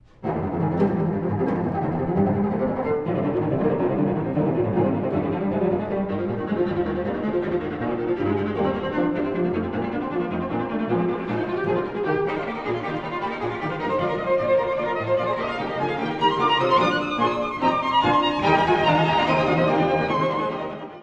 Presto
～急速に～
壮大なフーガの終楽章。
楽章とおして、あふれんばかりのエネルギー。